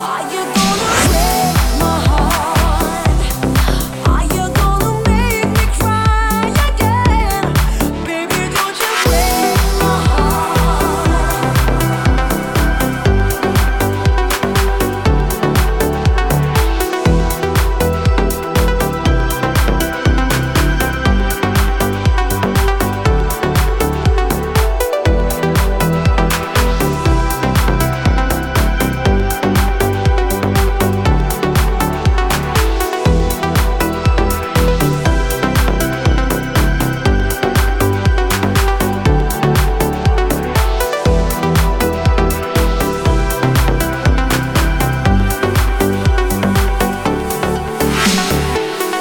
громкие
женский вокал
deep house
dance
EDM